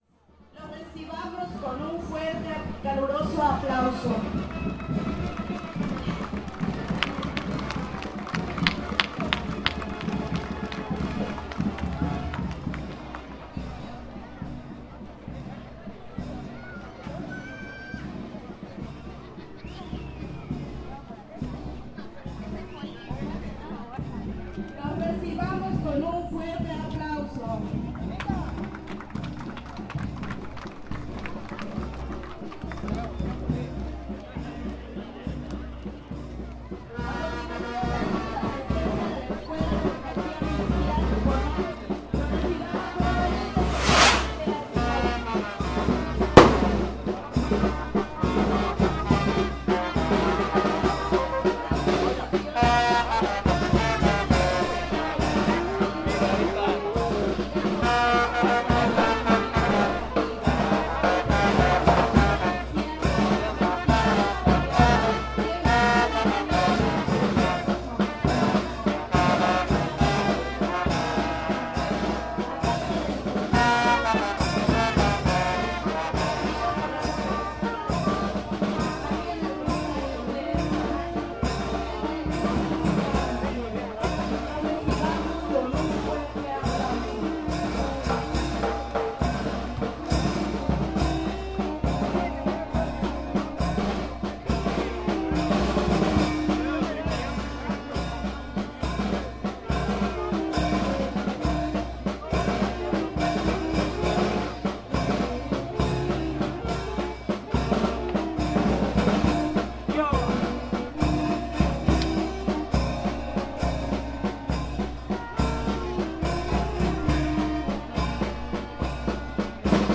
Desfile 20 de noviembre 2010
El presente es un registro sonoro del festejo del 20 de noviembre realizado en Zinacantán, Chiapas.